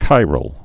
(kīrəl)